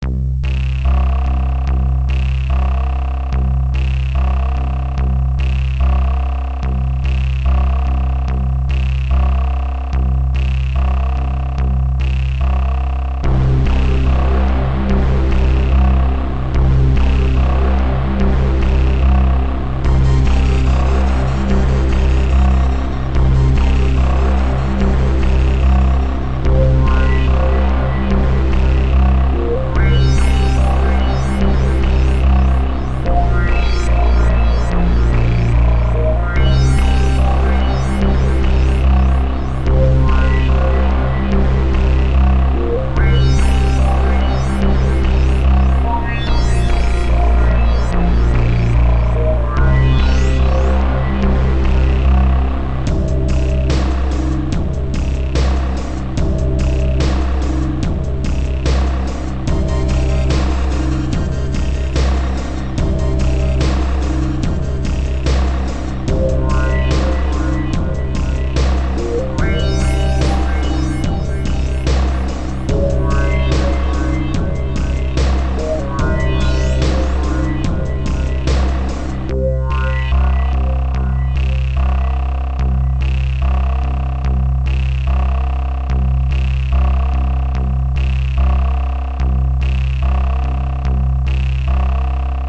Synth track suitable for a title screen for a scifi game.
• Music requires/does smooth looping